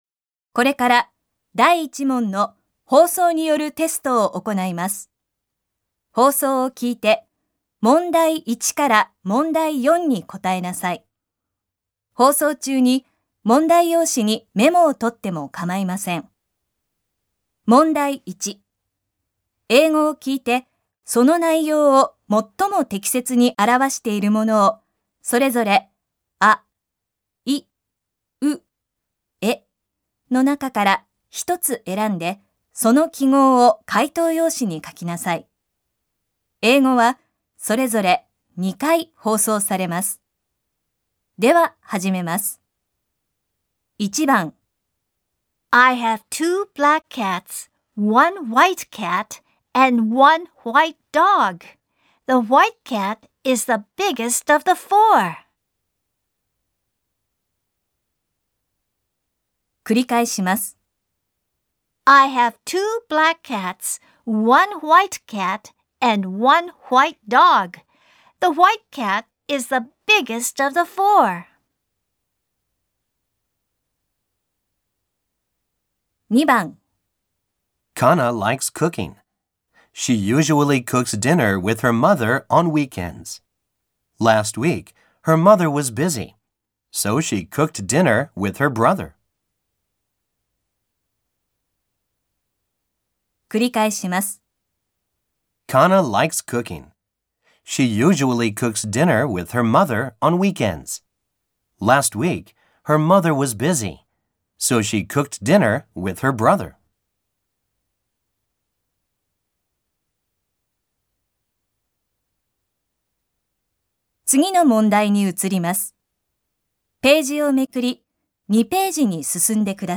3年生 第1回7月号 英語リスニング